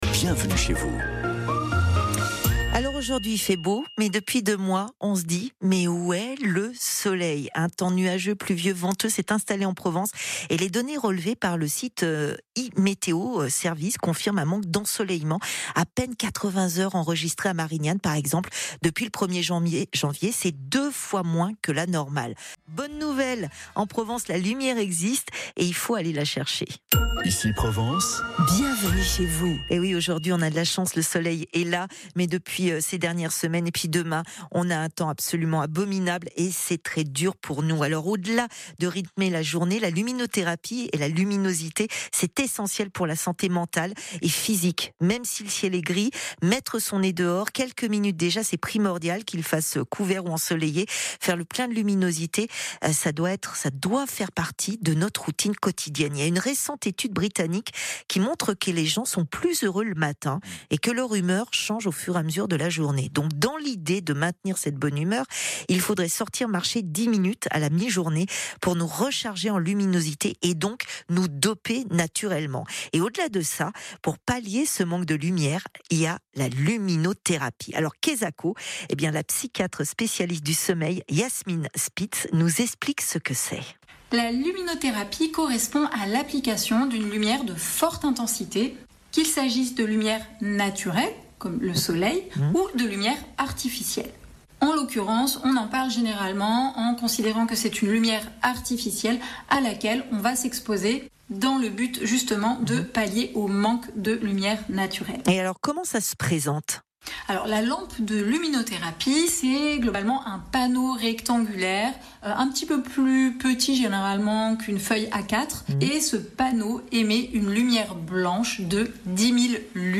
Comment remédier à ce manque de lumière en Feng Shui et Décoration ? Interview